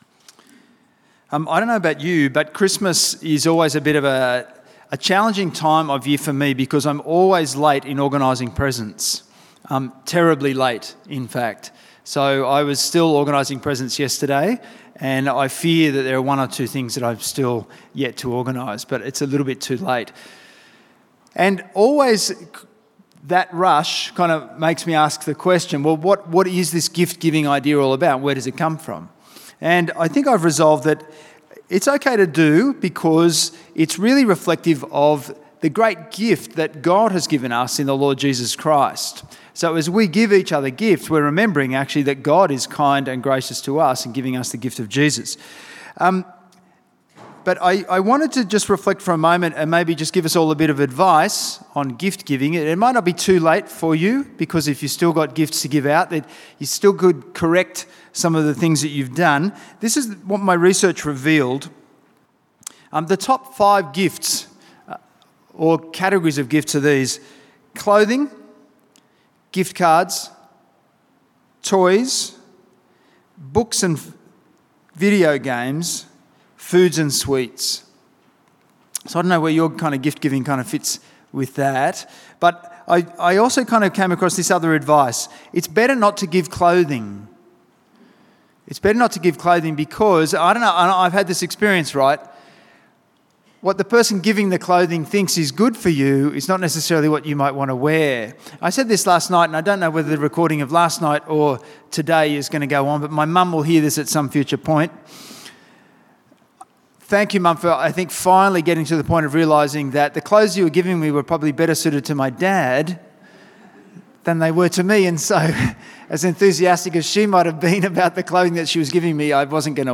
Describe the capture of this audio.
St Matthew's Anglican Church Wanniassa